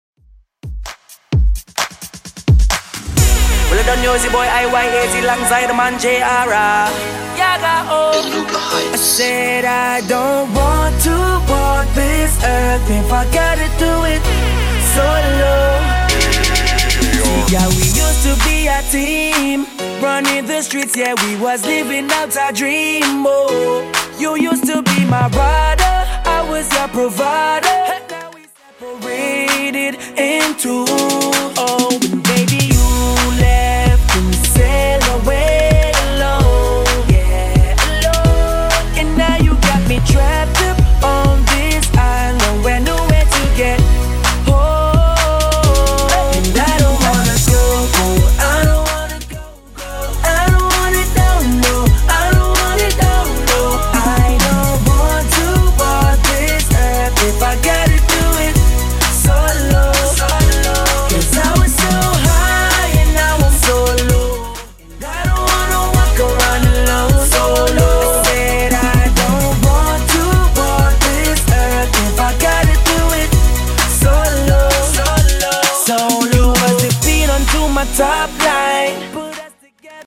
Genres: 2000's , RE-DRUM , TOP40
BPM: 130